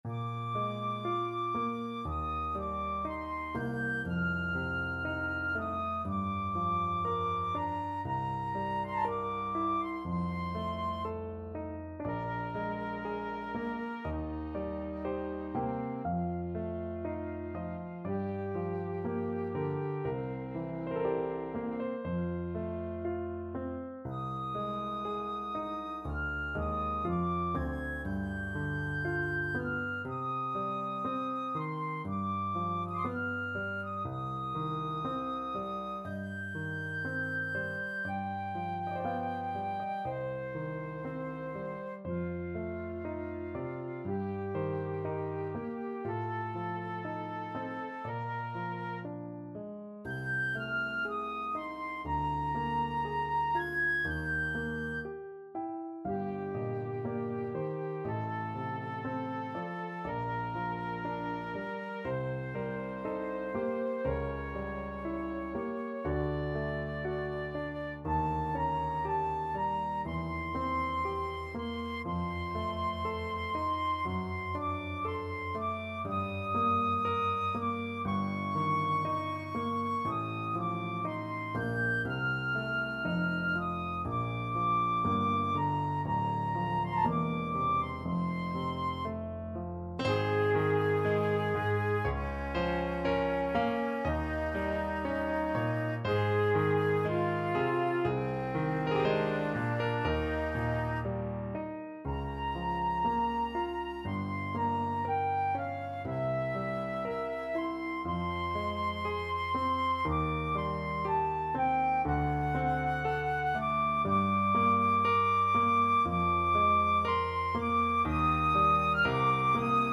Classical Chopin, Frédéric Cello Sonata, Op.65 Third Movement, Largo Flute version
Flute
3/2 (View more 3/2 Music)
~ = 60 Largo
Bb major (Sounding Pitch) (View more Bb major Music for Flute )
Classical (View more Classical Flute Music)